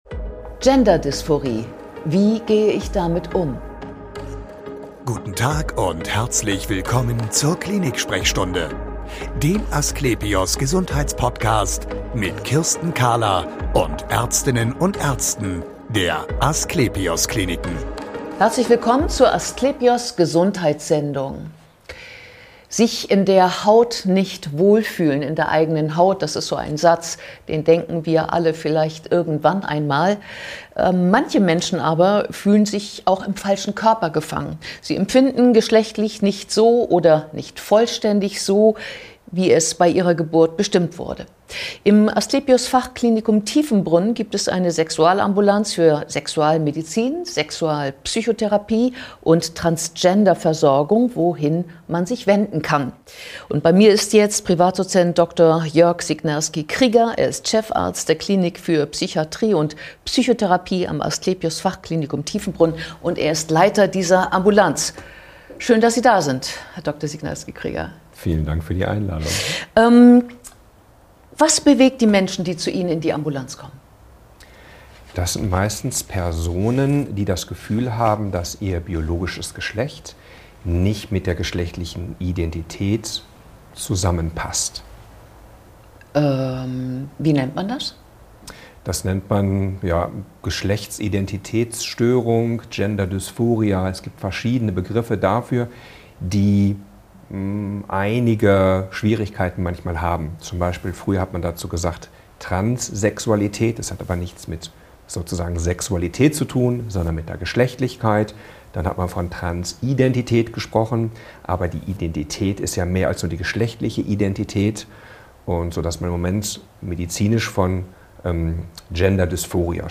Im Gespräch erklärt er, was in Menschen mit einer Gender-Dysphorie vorgeht, warum die vorgeschriebenen 12 Psychotherapie-Sitzungen oft gar nicht gebraucht werden und wie es nach der lang ersehnten Transition weitergeht.